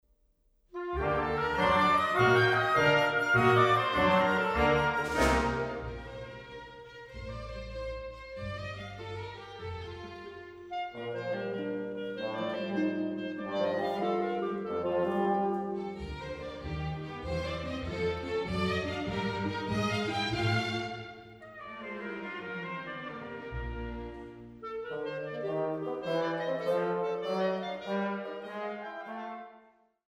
Orchesterwerke verfemter Komponisten